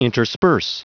Prononciation du mot intersperse en anglais (fichier audio)
Prononciation du mot : intersperse